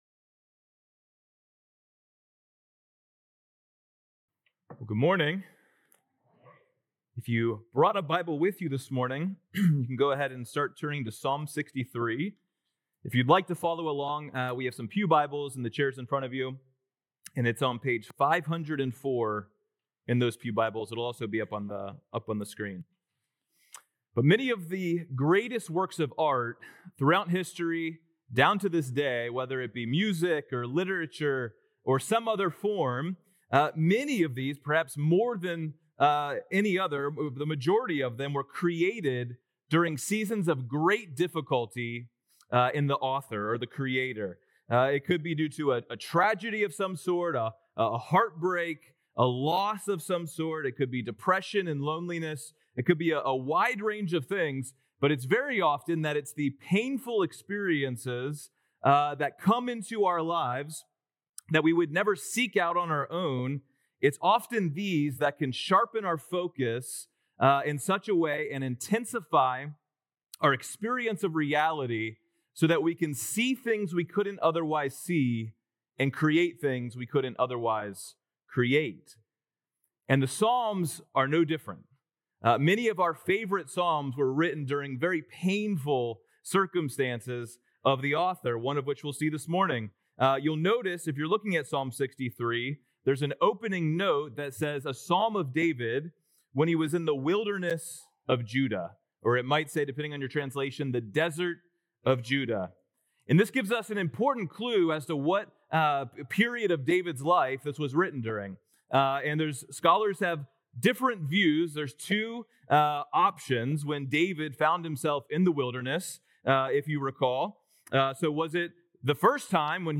Sept 7th Sermon